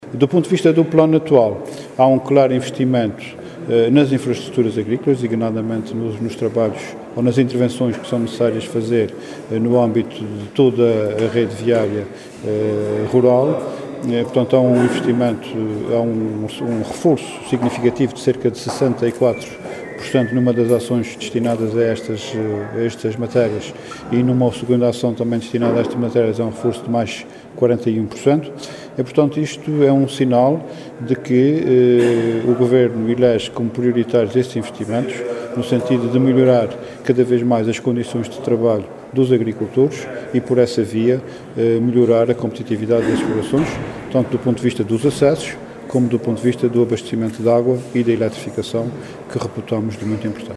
“O Governo elege como prioritários estes investimentos, no sentido de melhorar cada vez mais as condições de trabalho dos agricultores e, por essa via, melhorar a competitividade das explorações”, afirmou Luís Neto Viveiros em declarações aos jornalistas depois de ter sido ouvido na Comissão de Economia da Assembleia Legislativa.